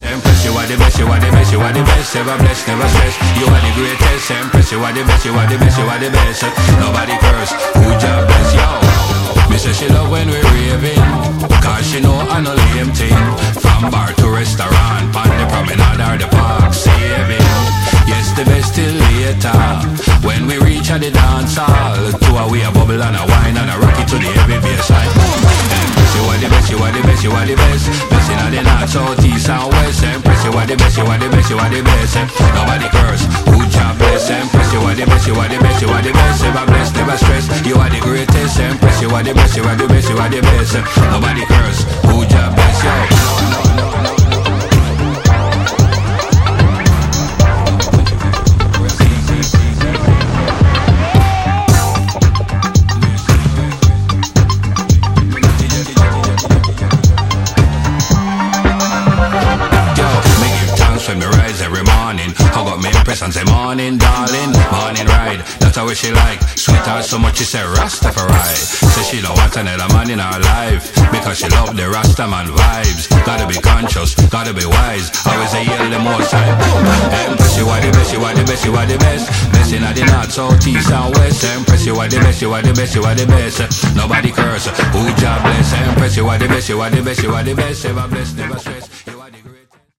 Big Disco Dub 12″
exploring the sweet spot between funk bass and echo chamber
signature ragga-comedy flow
uplifting lofi jazz funk vibes